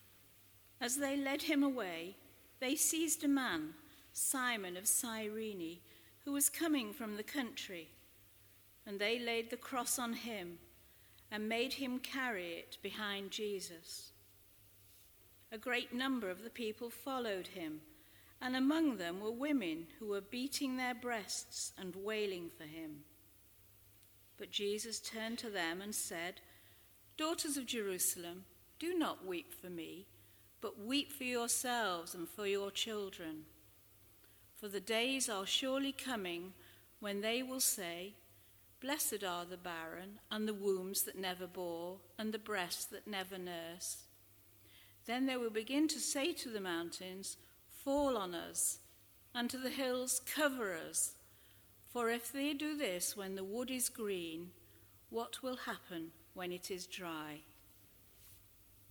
Four short sermons for Good Friday